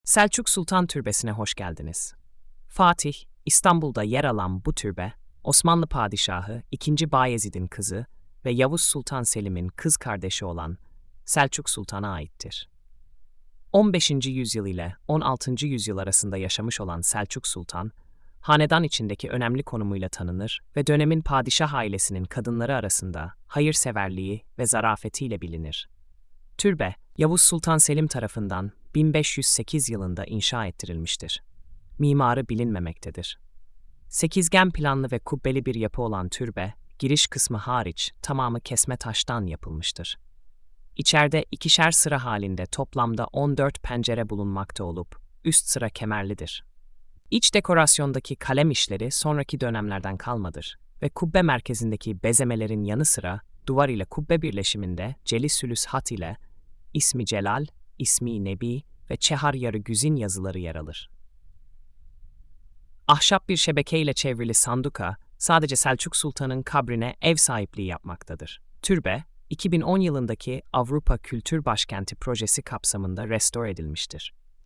SESLİ ANLATIM: